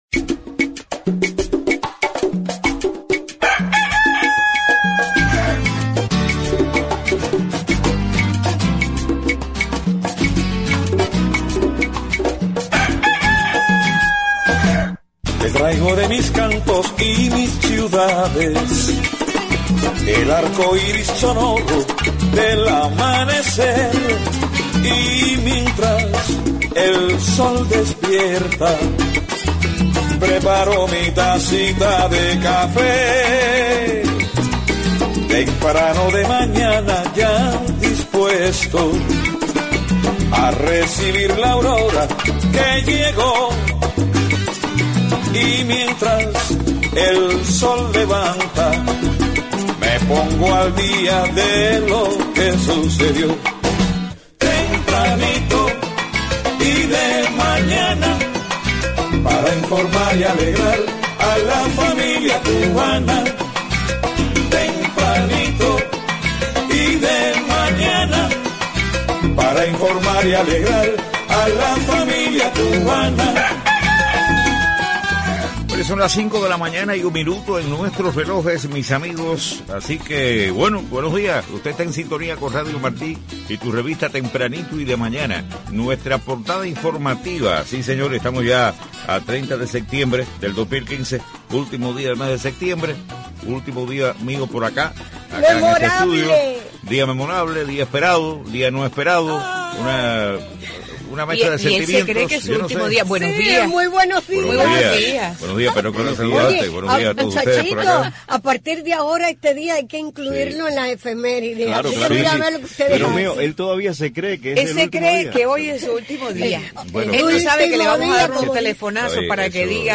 Hoy tuvimos un programa especial con muchos invitados